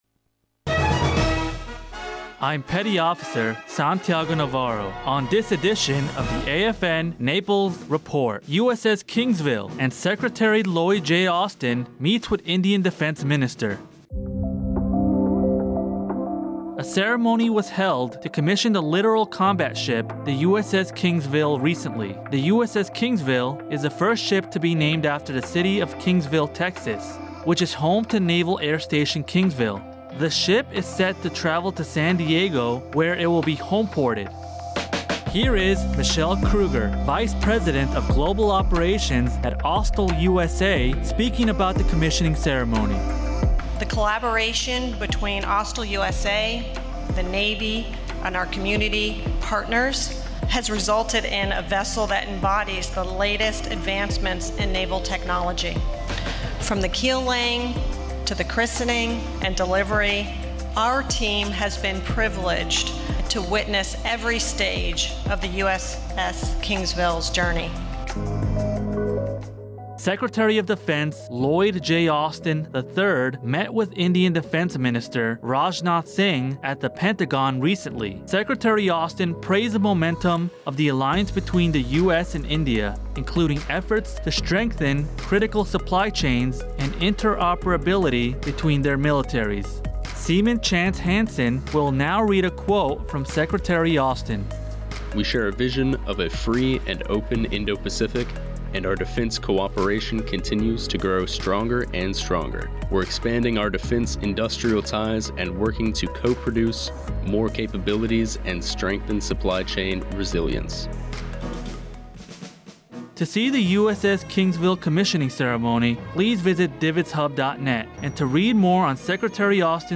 Radio news highlighting the USS Kingsville commissioning ceremony and General CQ Brown's meeting with the Indian Defense Prime Minster.